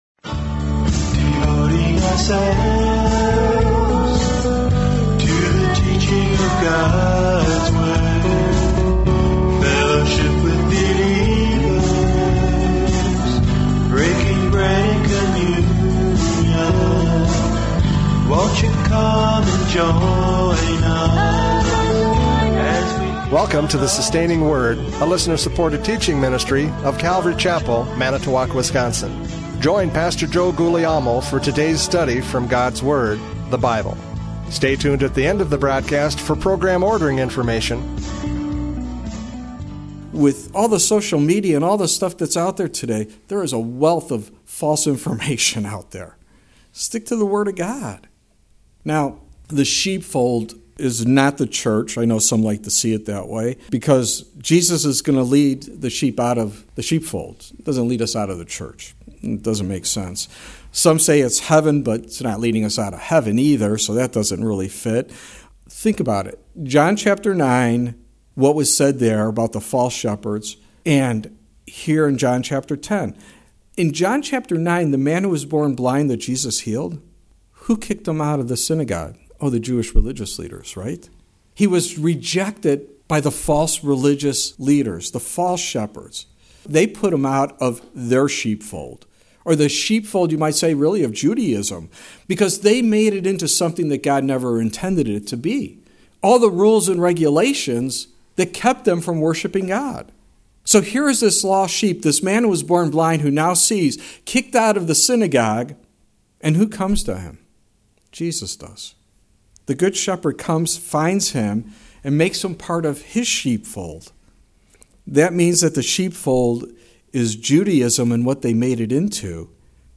John 10:1-10 Service Type: Radio Programs « John 10:1-10 The Good Shepherd!